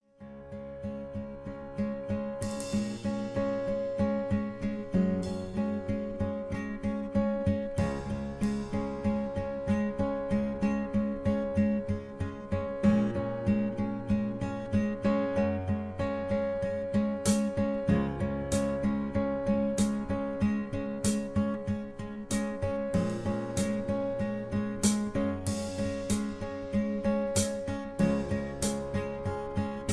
(Key-Db) Karaoke Mp3 Backing Tracks